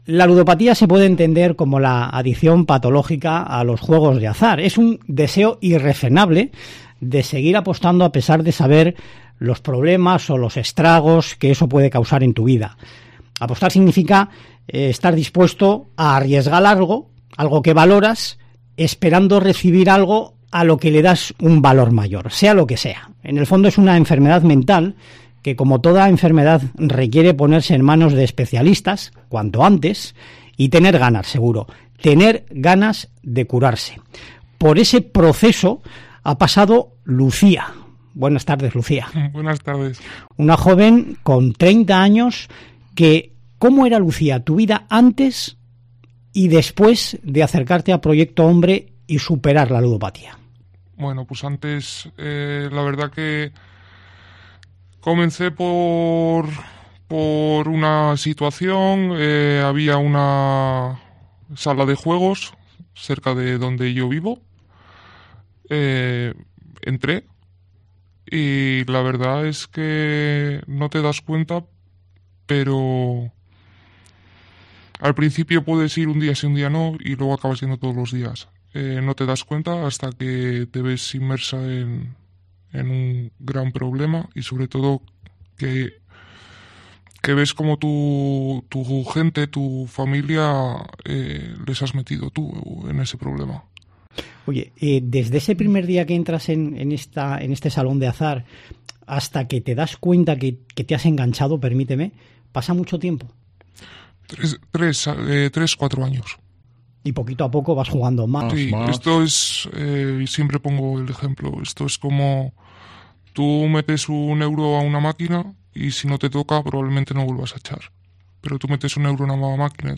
esta entrevista en Cope